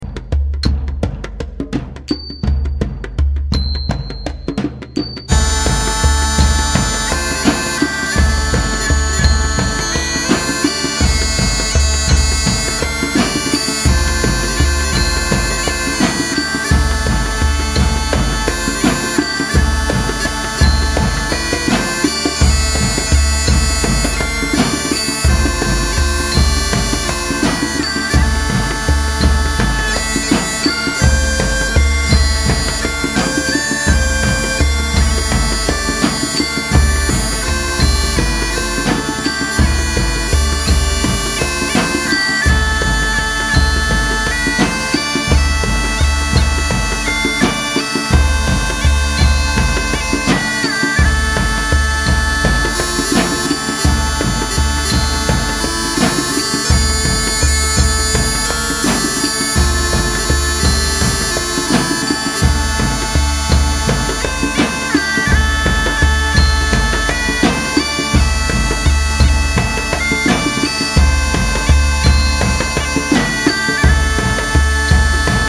MittelatlerJazz und Weltmusik